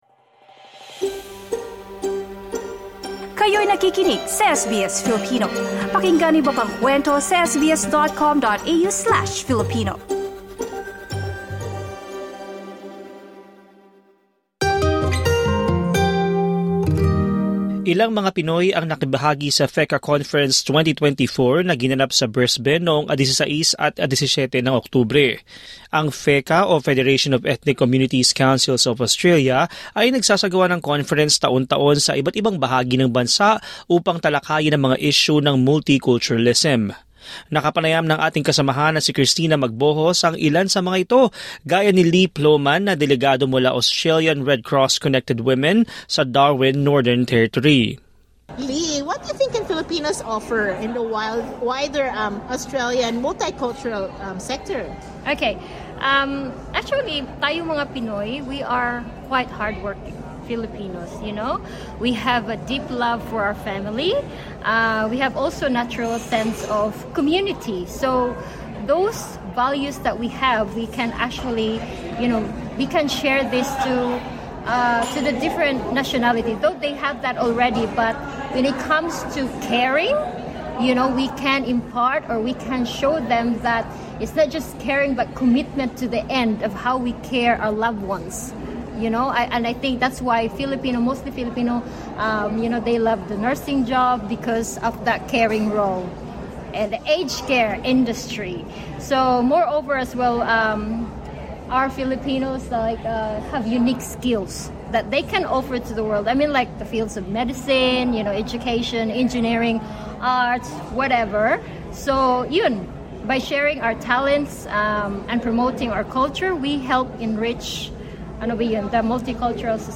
Nakapanayam ng SBS Filipino ang ilang migranteng Pilipino na delegado sa FECCA Conference 2024 at inilatag ang kanilang partisipasyon.